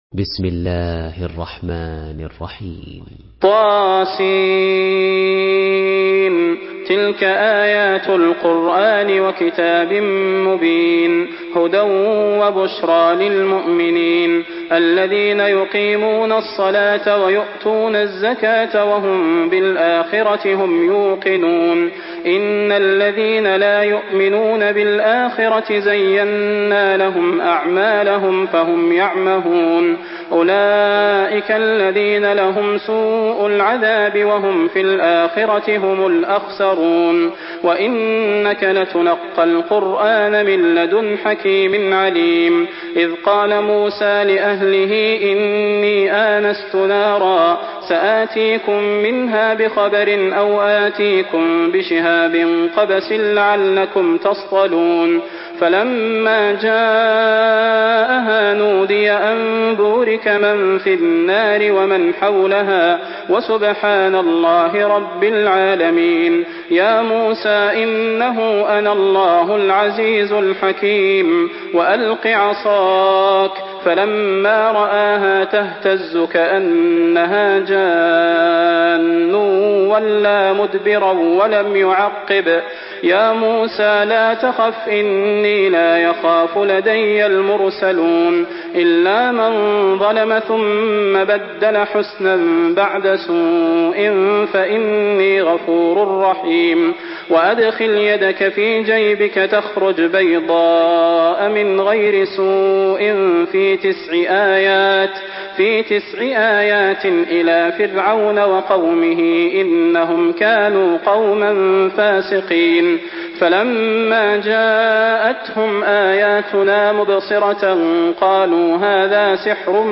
Surah An-Naml MP3 in the Voice of Salah Al Budair in Hafs Narration
Murattal Hafs An Asim